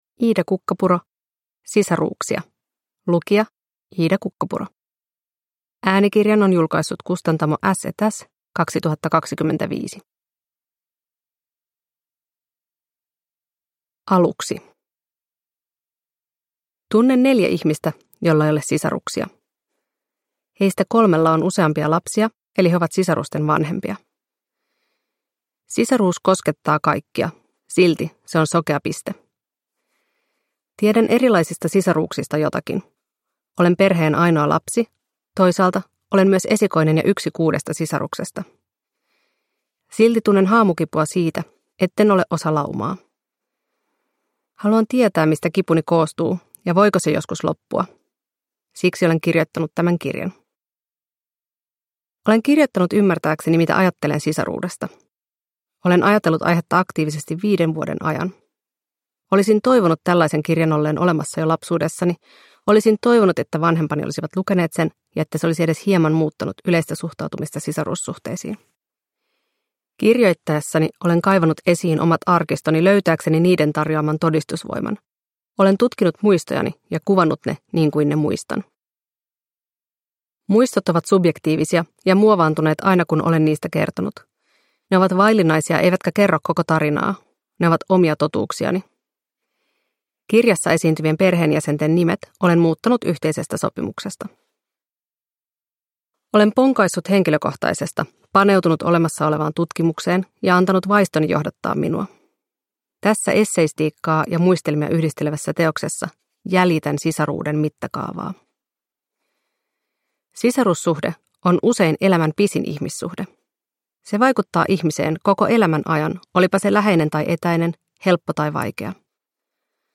Sisaruuksia – Ljudbok